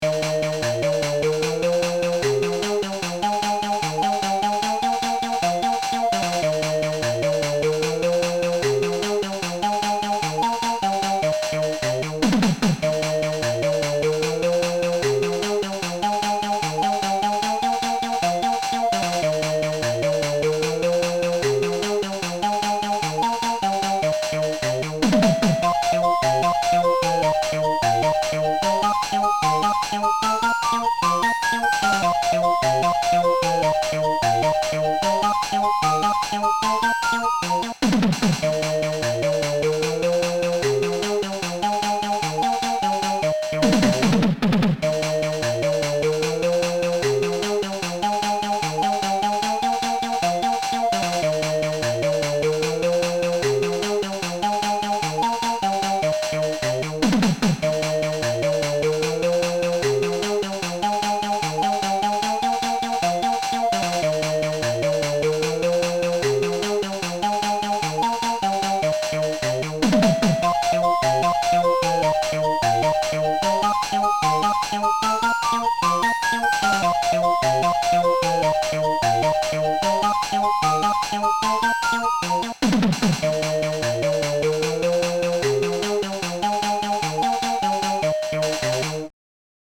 Cover